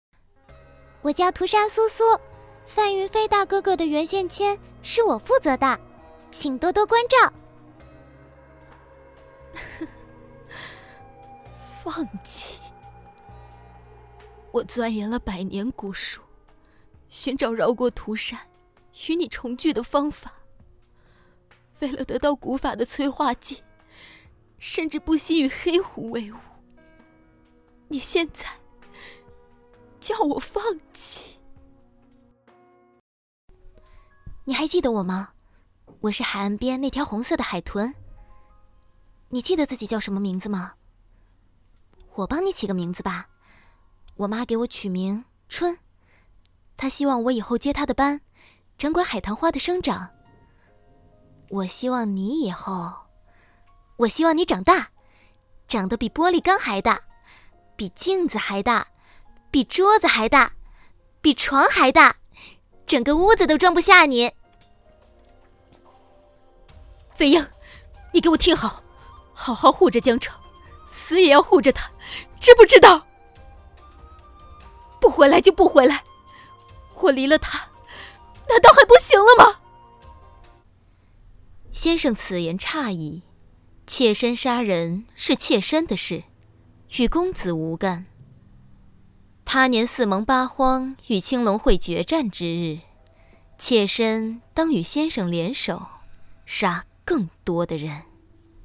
国语青年亲切甜美 、女课件PPT 、工程介绍 、绘本故事 、动漫动画游戏影视 、旅游导览 、30元/分钟女2 国语 女声 游戏oc成品 亲切甜美